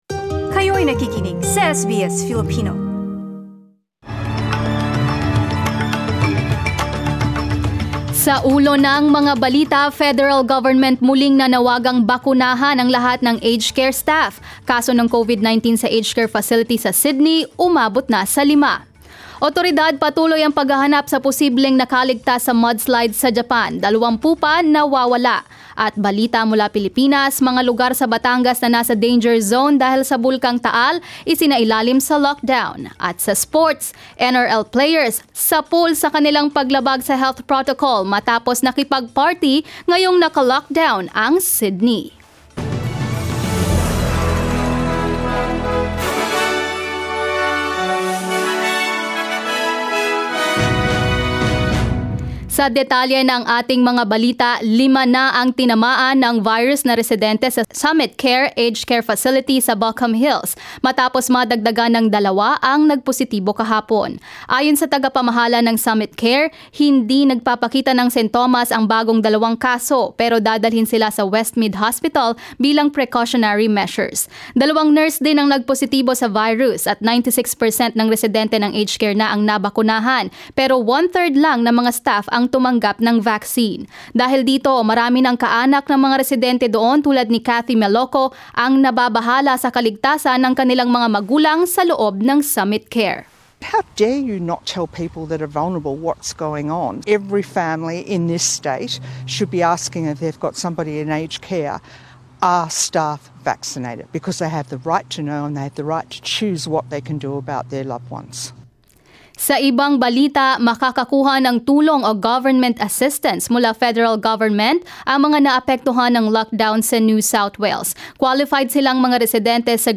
SBS News in Filipino 5 July